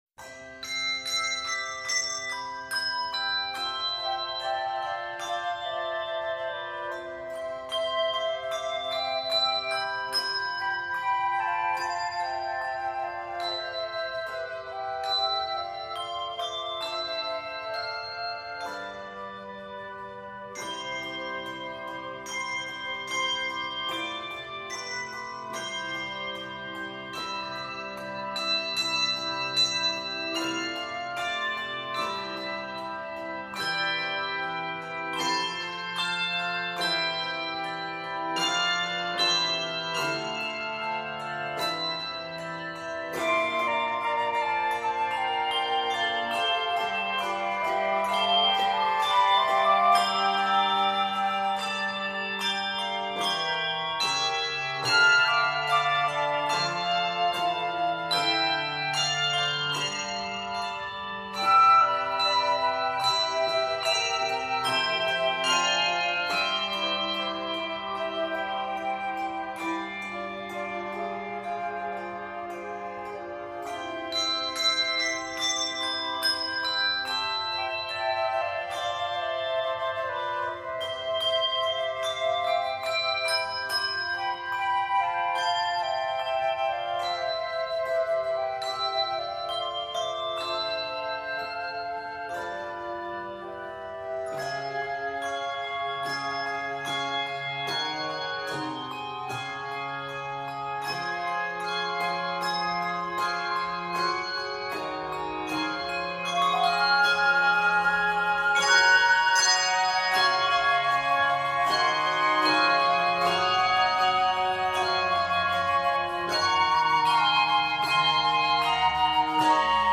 lyrical hymn arrangement
3-6 octaves and two optional flutes